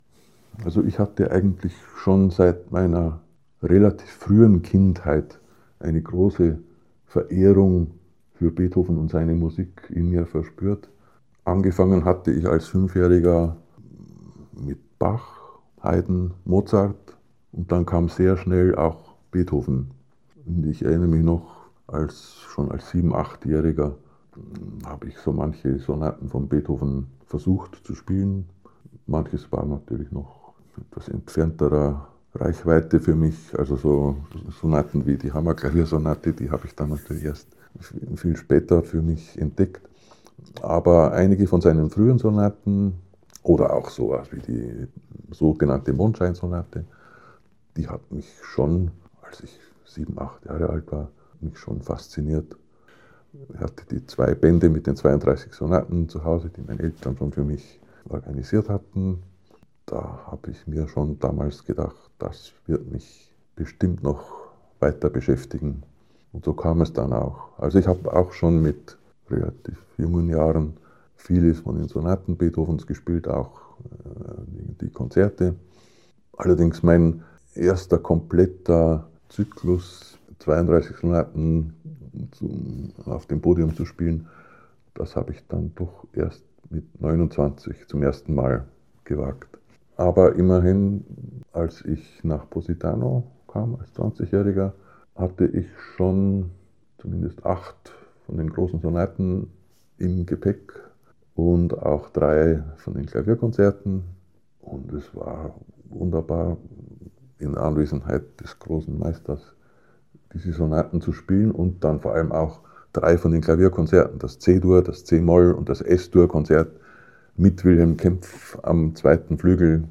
In eight chapters, he reports on the masterclasses at Casa Orfeo and his encounters with Wilhelm Kempff.